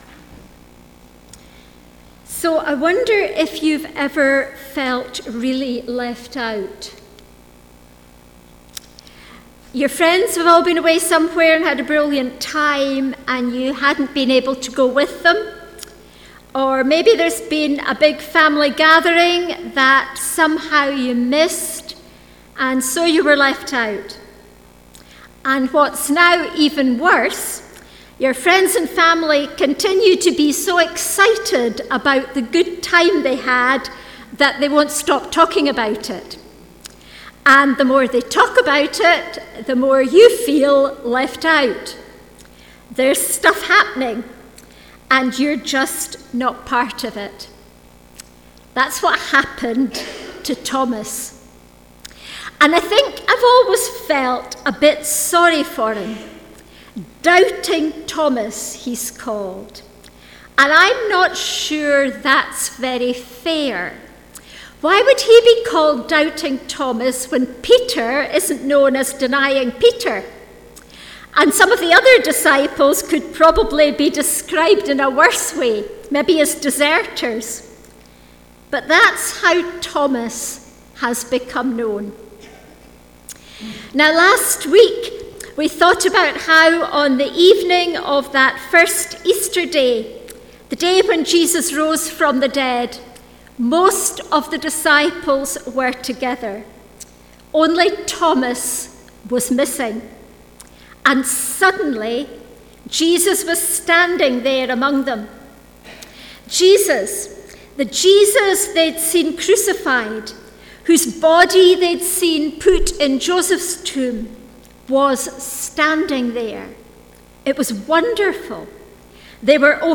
19-31 Service Type: Sunday Morning Thomas